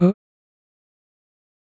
Audio / Bleeps / dalvTalk.wav